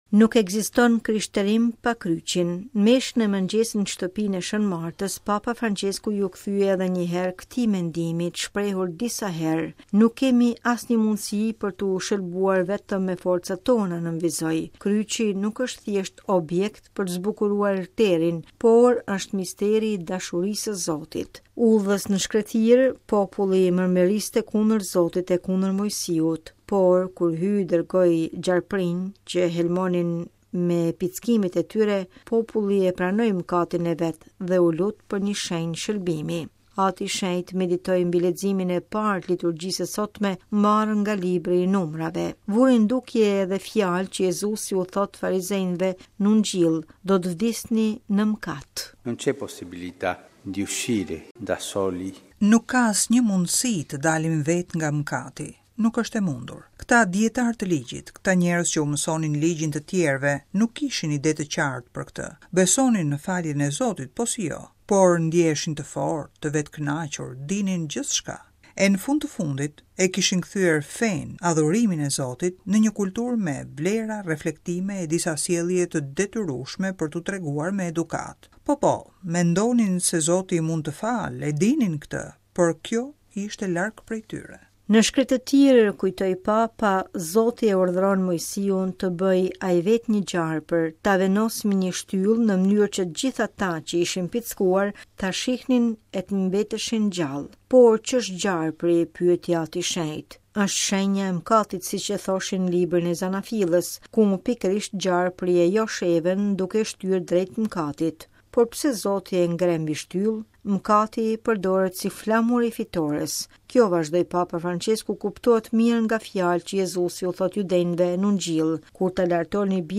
Në meshën e mëngjesit në Shtëpinë e Shën Martës, Papa Françesku iu kthye edhe një herë këtij mendimi, të shprehur disa herë.